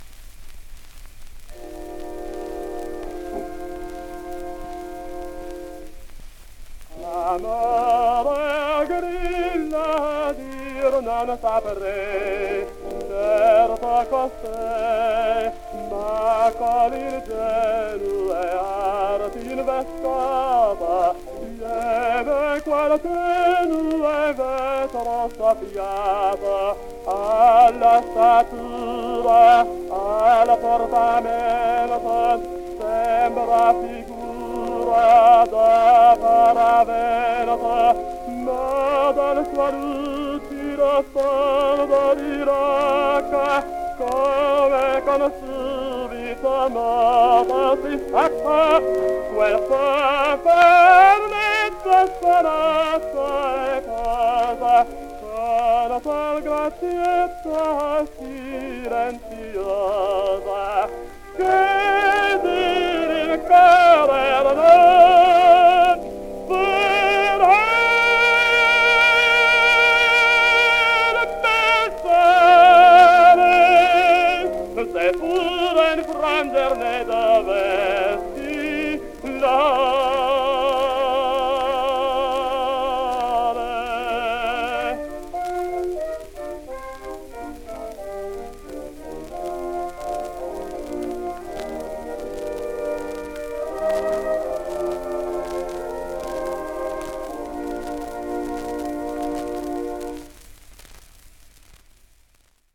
Riccardo Martin singsMadama Butterfly: